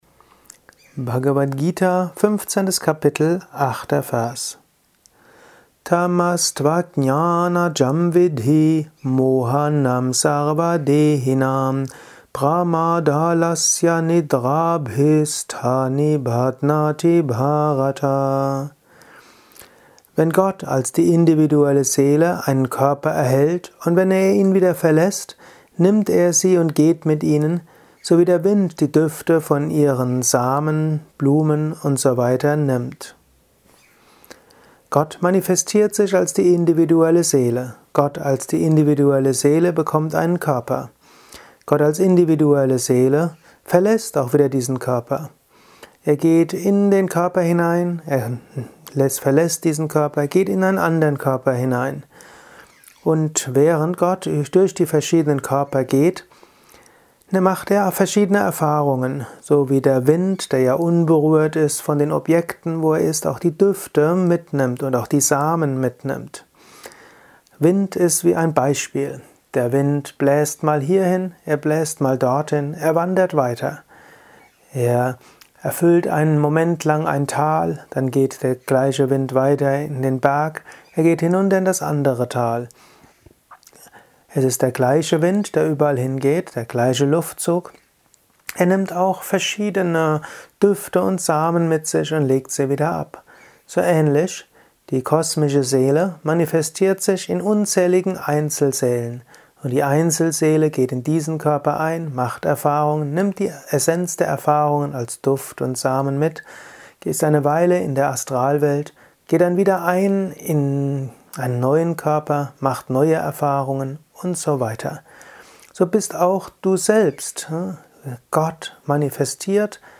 Dies ist ein kurzer Kommentar als Inspiration für